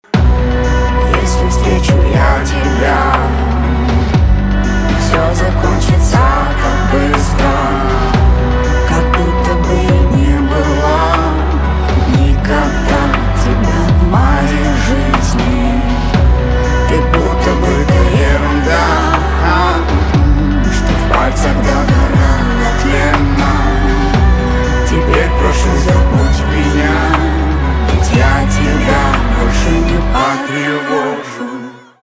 русский рэп , битовые , басы
грустные , печальные